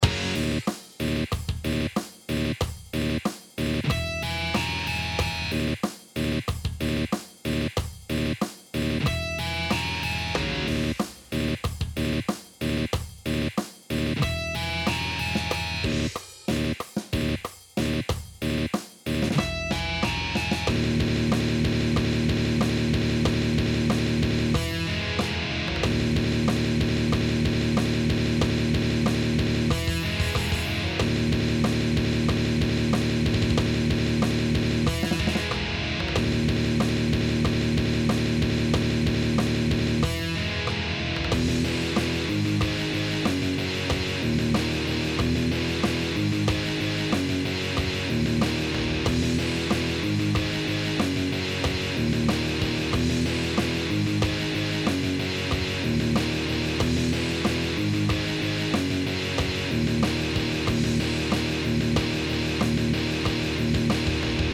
heavy, intense Gallop Breakdown.